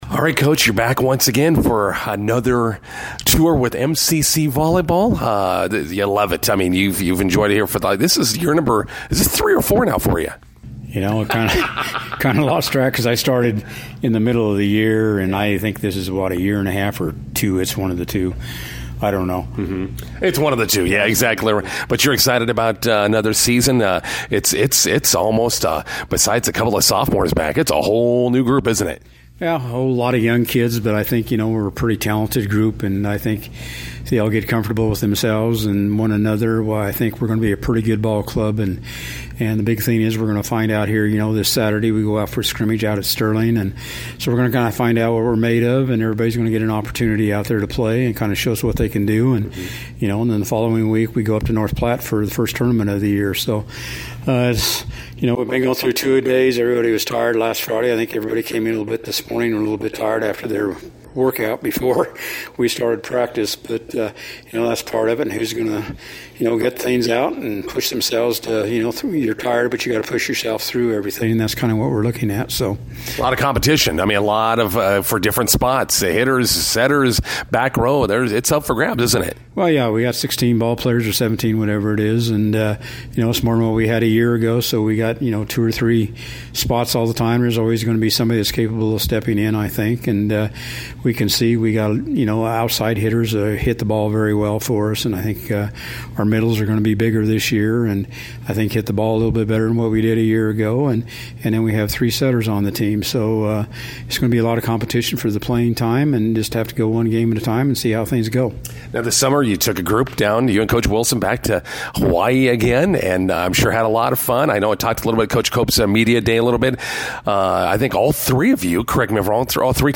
INTERVIEW: Area high school volleyball players compete in Hawaii’s NoKaOi Volleyball Showcase this summer.